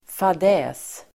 Ladda ner uttalet
Uttal: [fad'ä:s]